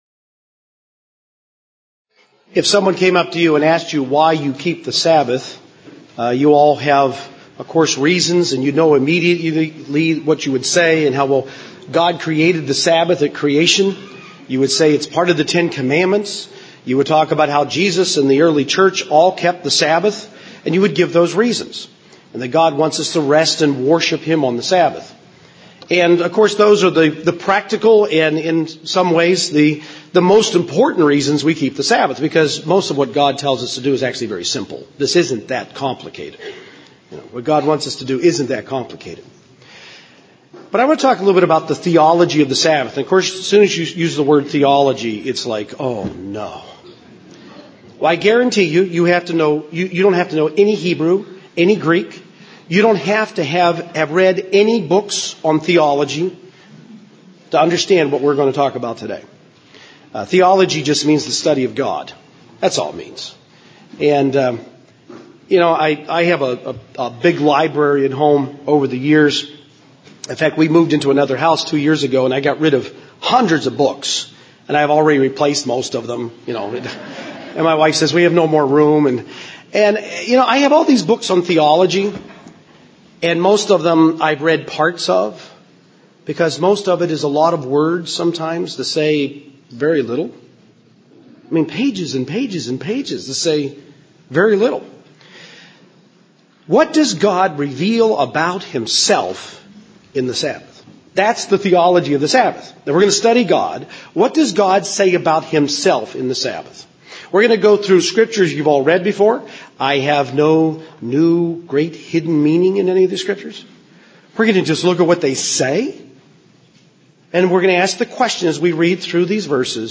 Kerrville 2009 Feast of Tabernacles seminar. What does God reveal about Himself in the Sabbath?